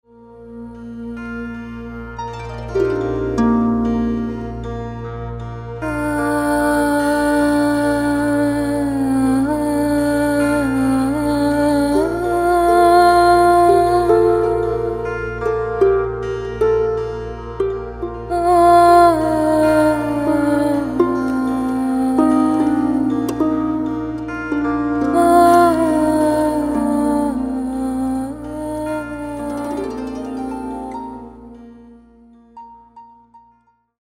Inspired by Indian classical and crossed into the Irish harp